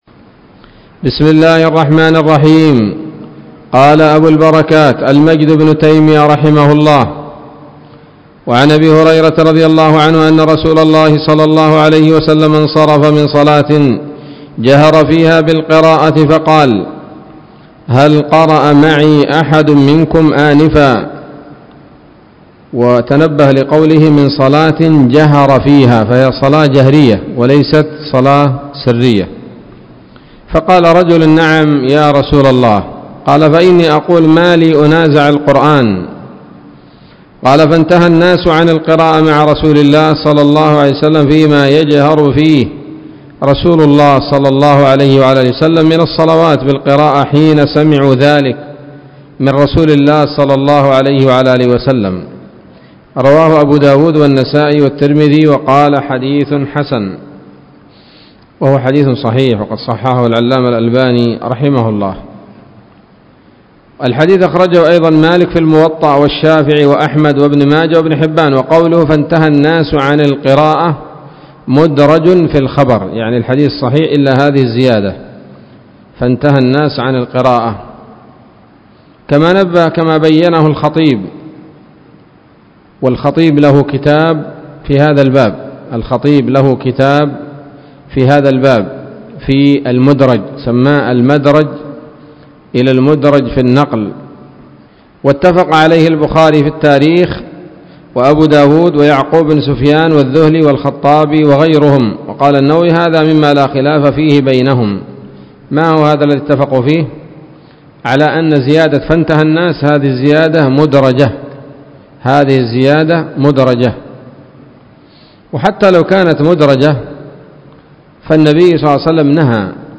الدرس الثالث والثلاثون من أبواب صفة الصلاة من نيل الأوطار